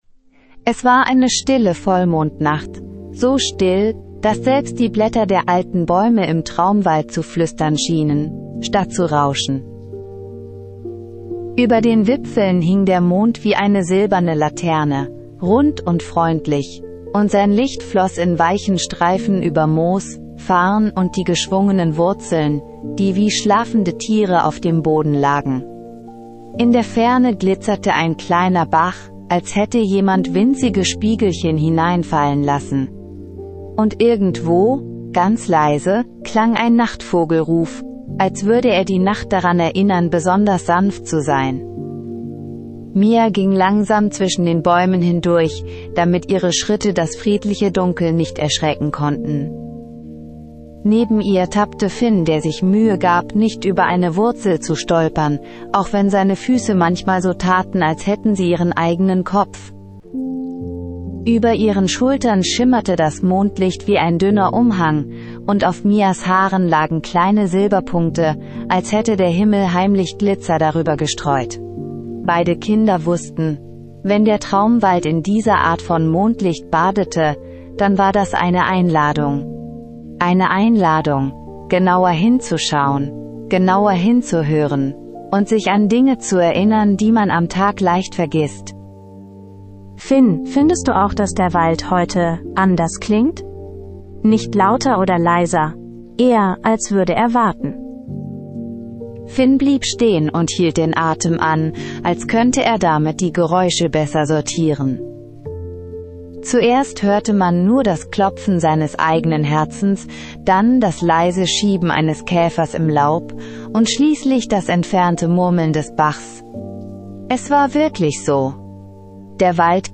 Lunaris bringt Mia und Finn bei, wie man das Lied der Sterne hören kann. Ein magischer Sternenregen erfüllt den Traumwald mit Melodien. Eine magische Einschlafgeschichte (ca. 58 Min).